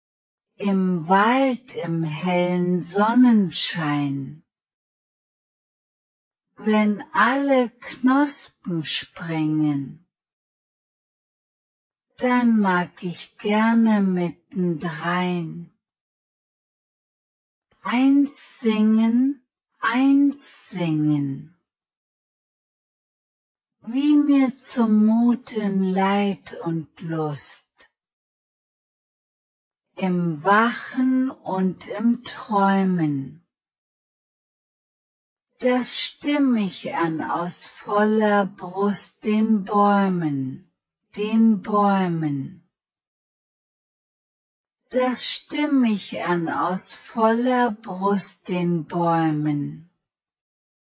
voix femme(1-21)
im-wald-prononce-fille-1-21.mp3